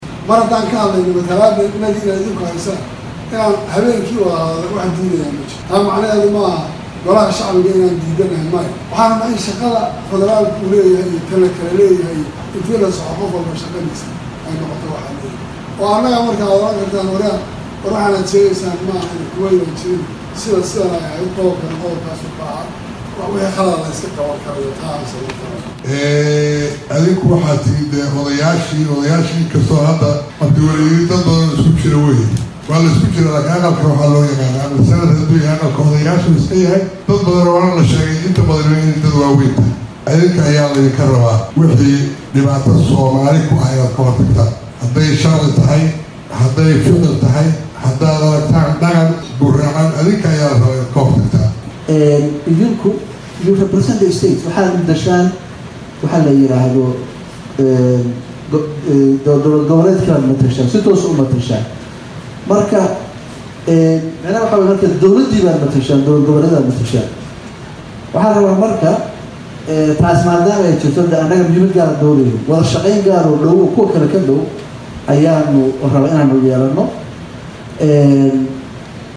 Dhageyso: Madaxda Maamul Goboleedyada dalka oo Khudbado gaaban u jeediyay Golaha Aqalka Sare | Goobsan Media Inc
Kulanka 15 aad Ee kala Fadhiga 2aad Ee Golaha Aqalka sare Waxaa Maanta ka qeyb galay oo Horyimid Xildhibaanada Aqalka Sare Madaxweynayaasha Dowlad Goboledyada Soomaaliya
Dhammaan Madaxda maamul goboleedyadda ayaa khudbad ay kaga hadlaayeen Xaaladda Soomaaliya waxa ay u jeediyeen Xildhibaanada Aqalka Sare.
Qudbada-madaxda-maamul-goboleedyada.mp3